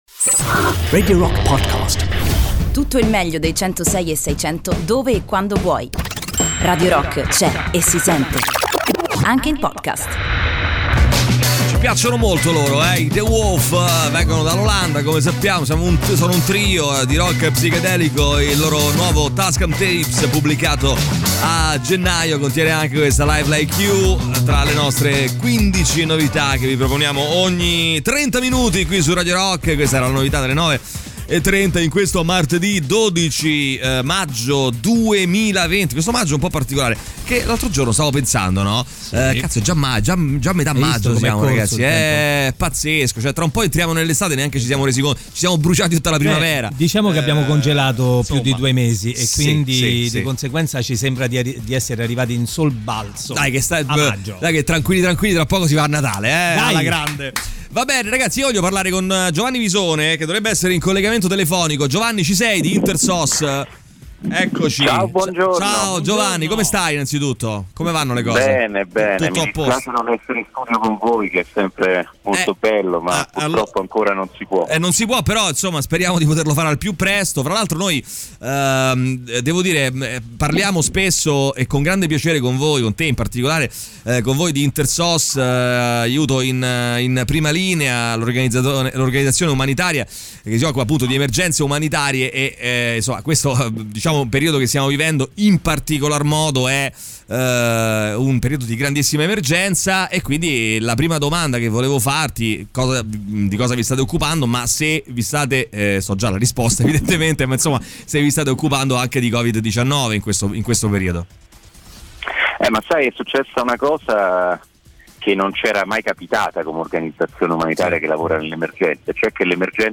Intervista
in collegamento telefonico